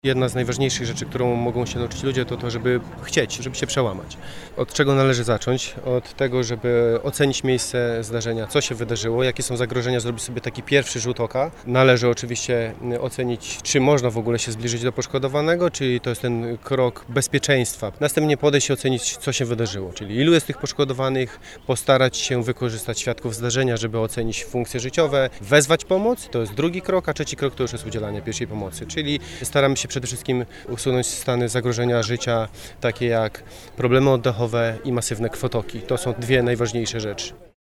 W centrum Wrocławia (pl. Nowy Targ) zorganizowano plenerowe szkolenie z zakresu udzielania pierwszej pomocy medycznej.